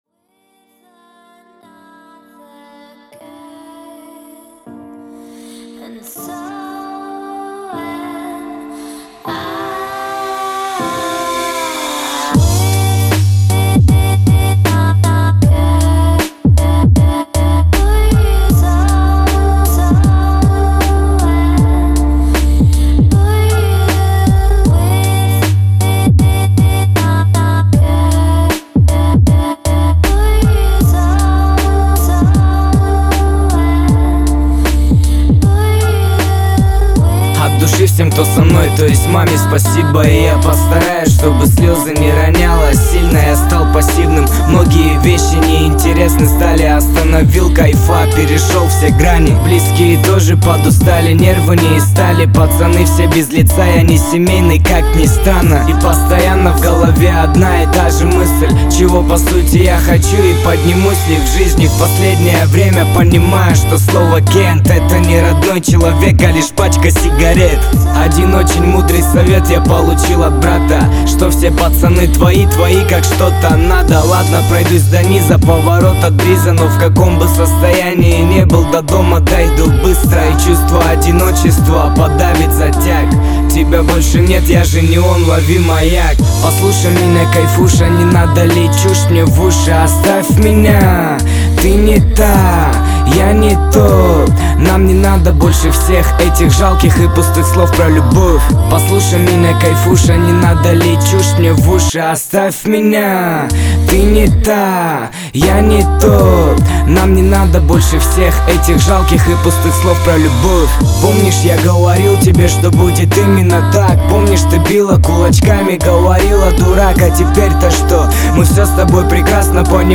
• Качество: 255 kbps, Stereo